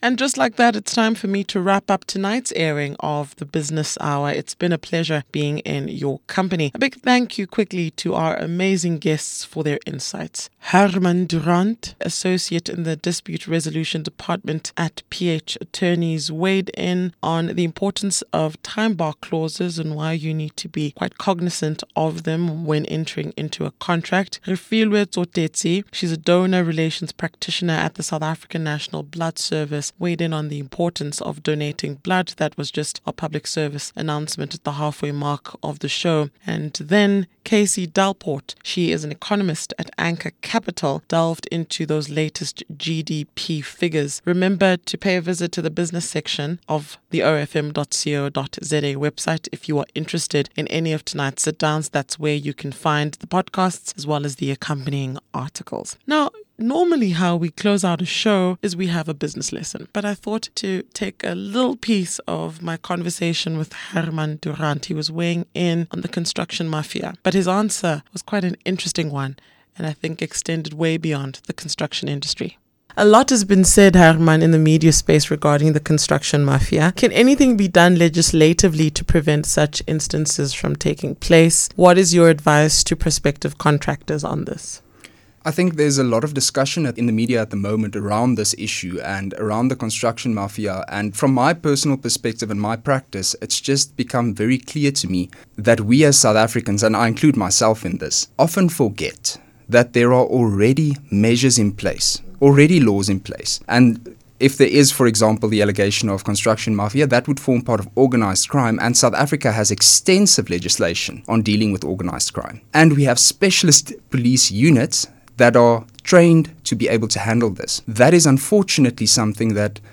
LISTEN to the closing question of the OFM interview.mp3